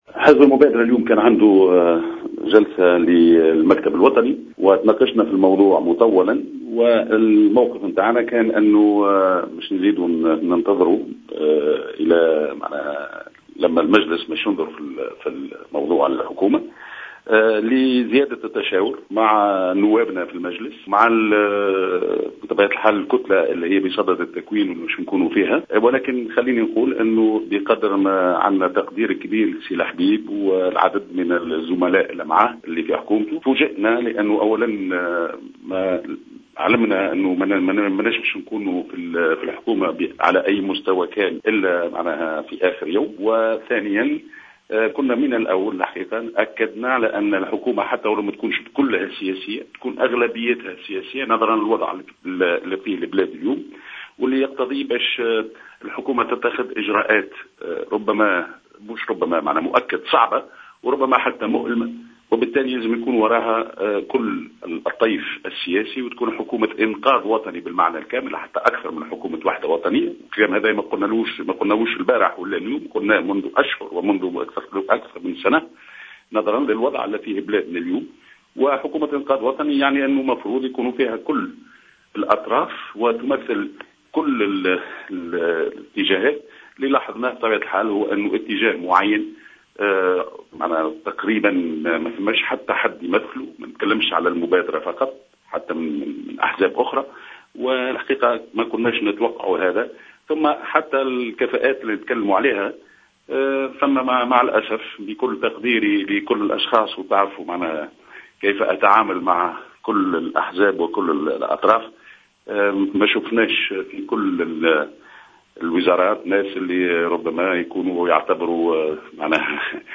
Le président du parti Al Moubadra, Kamel Morjane, a affirmé aujourd’hui dans une intervention sur les ondes de Jawhara FM, que le gouvernement Essid n’est pas un gouvernement politique.